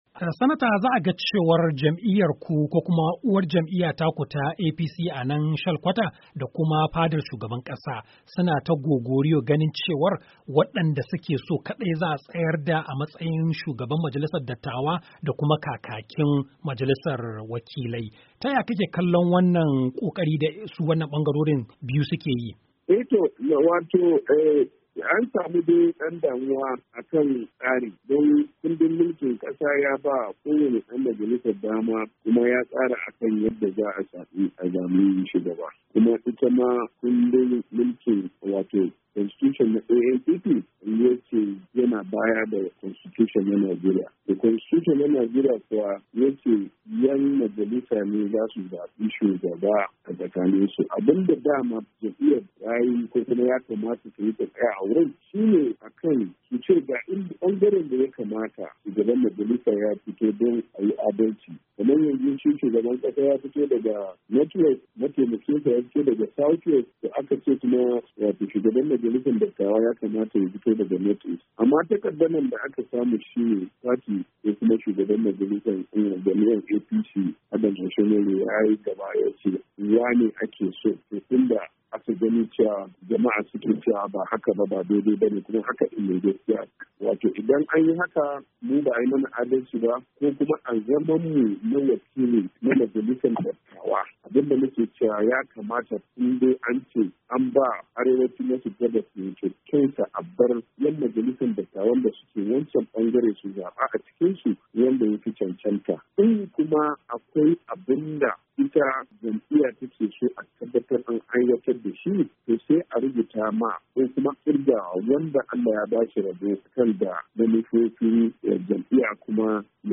Ga kadan daga cikin hira da Sanata Ali Ndume.